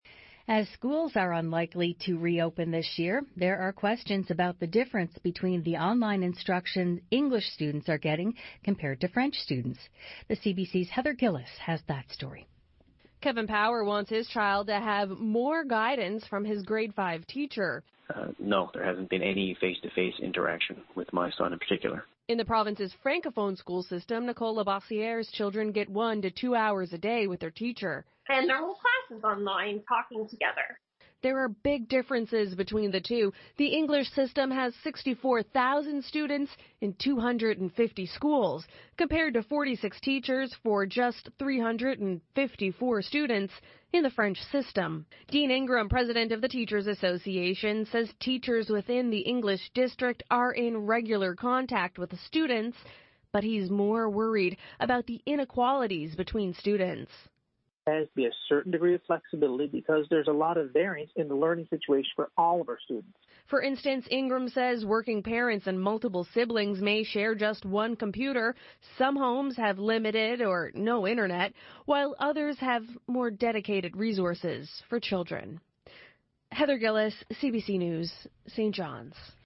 Media Interview - CBC News - April 29, 2020